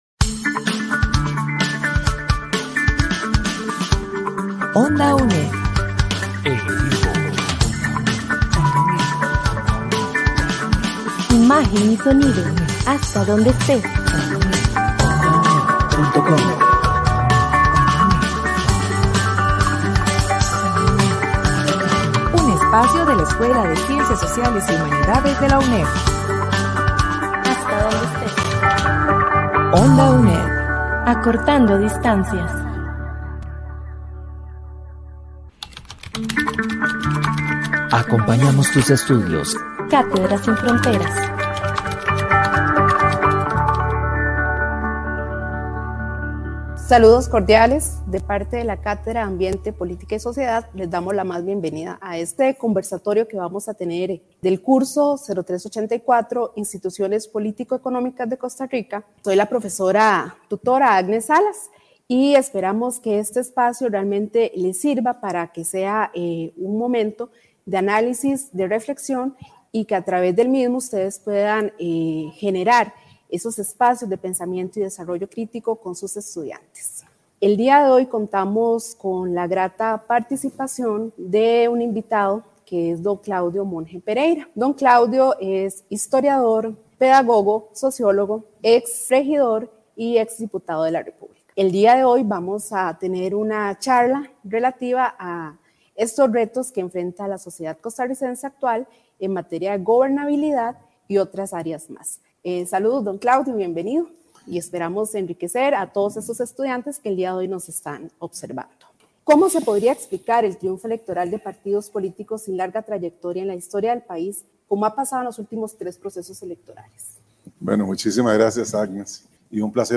Programas de RADIO RADIO Retos del actual sistema Politico costarricense Su navegador no soporta la reproducci�n de audio HTML5.